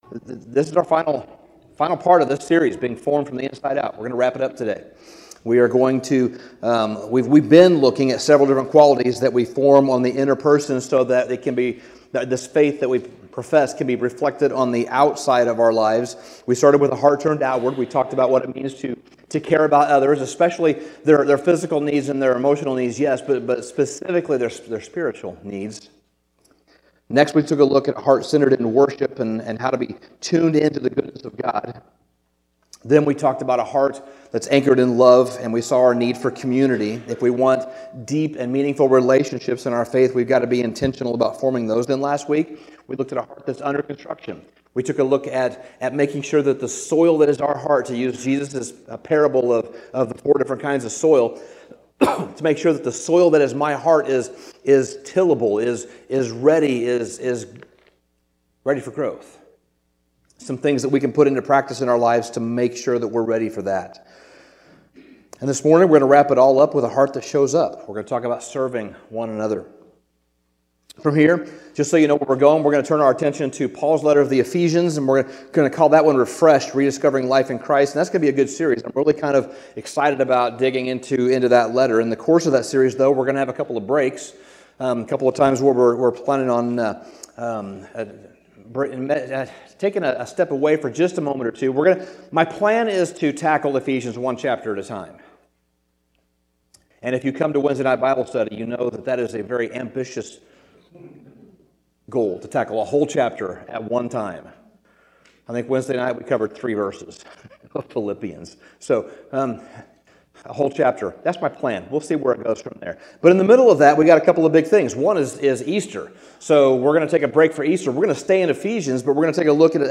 Sermon Summary Spiritual growth rarely happens in comfort; it often grows in the soil of service.